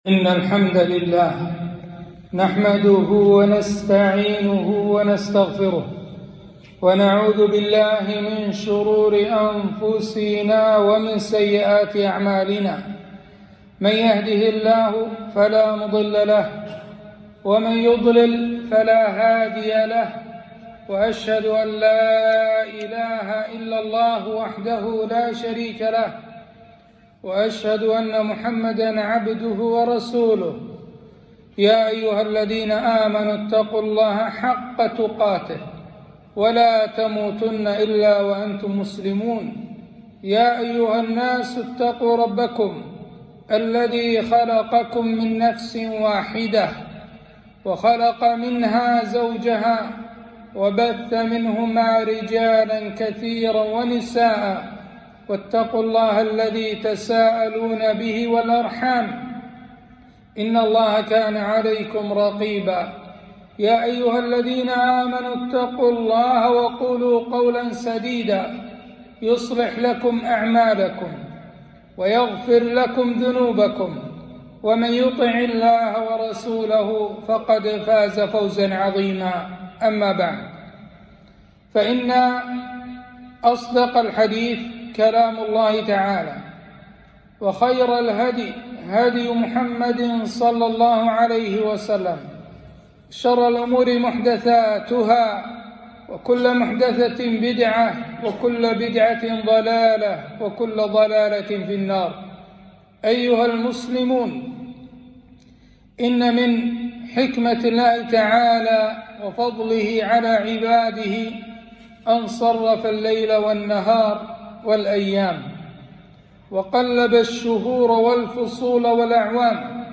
خطبة - الأحكام الشرعية لفصل الشتاء